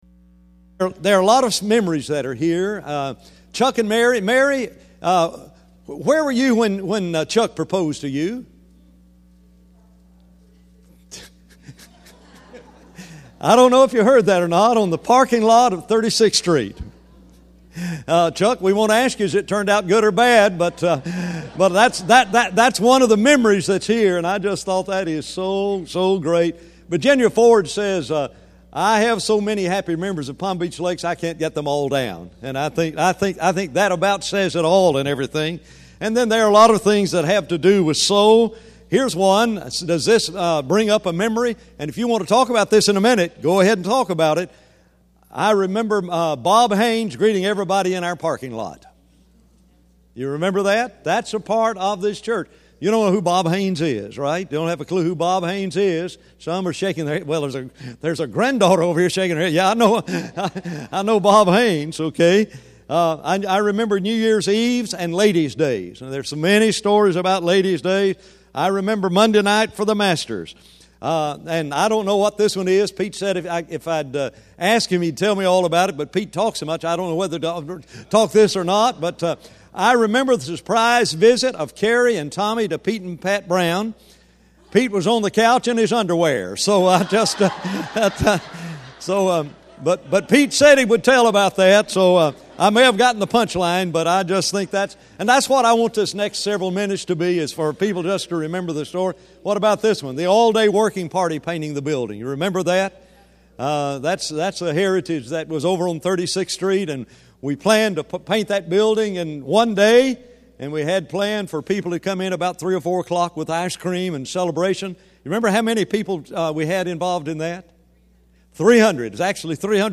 Homecoming Weekend
Sermon Audio